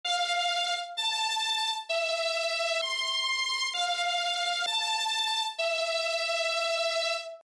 хорошая композиция , может быть слегка затянута , кое где звучит резко и резонирующие звуки , по моему неплохо бы было , если бы звуки расположить поглубже в пространстве перед слушателем , создать больший объем , сторонние басовые линии не помешали бы наверное .